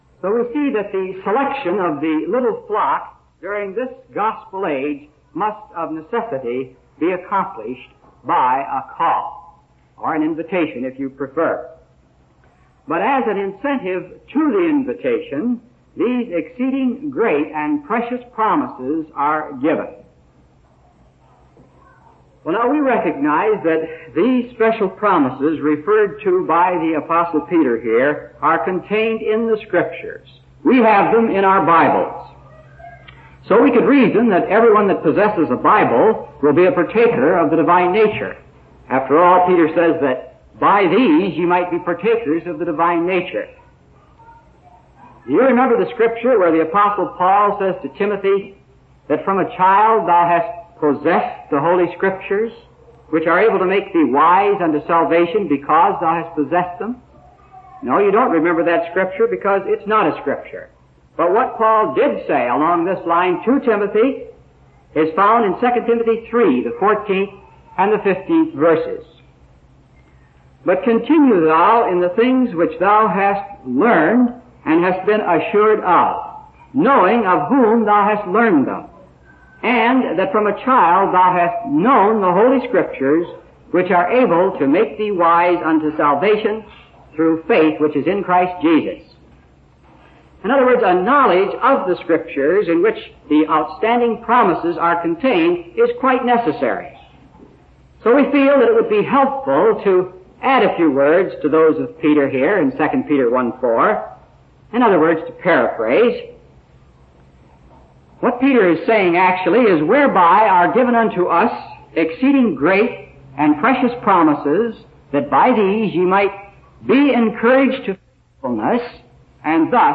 From Type: "Discourse"
Vancouver Canada Convention date unknown